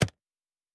pgs/Assets/Audio/Sci-Fi Sounds/Interface/Click 7.wav at master
Click 7.wav